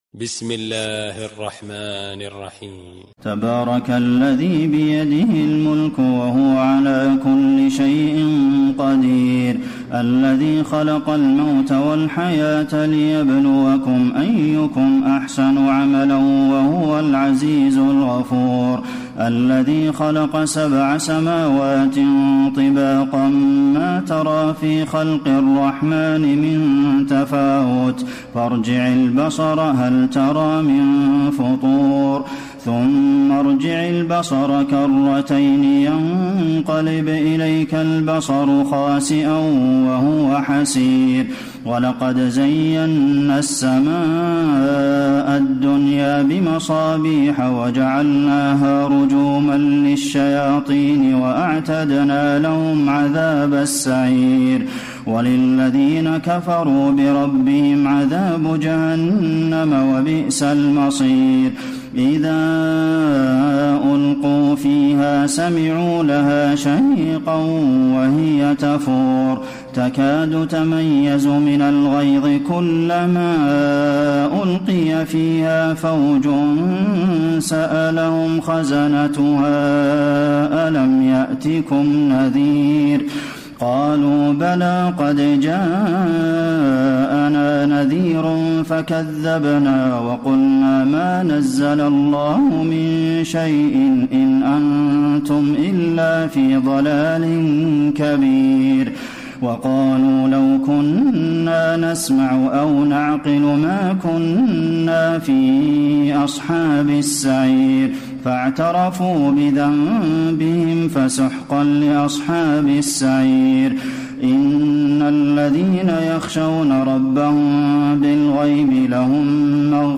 تراويح ليلة 28 رمضان 1434هـ من سورة الملك الى نوح Taraweeh 28 st night Ramadan 1434H from Surah Al-Mulk to Nooh > تراويح الحرم النبوي عام 1434 🕌 > التراويح - تلاوات الحرمين